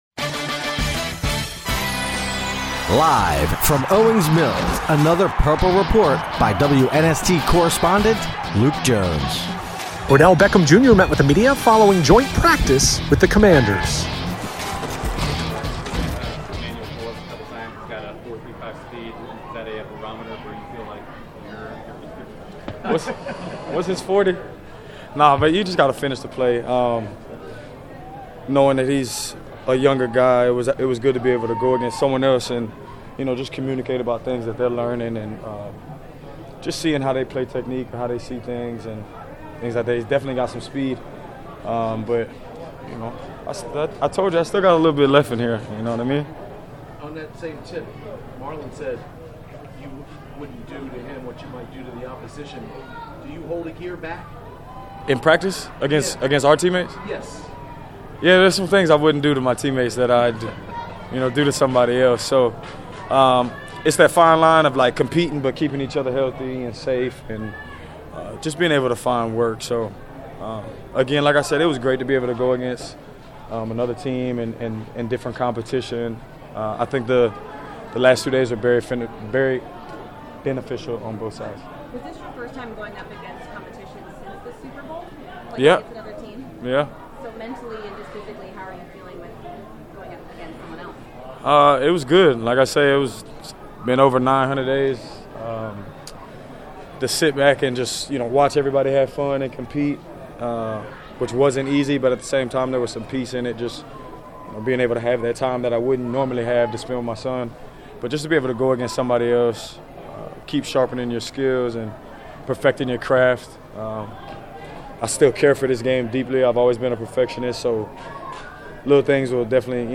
Odell Beckham Jr. talks with reporters after joint practice with Washington
Locker Room Sound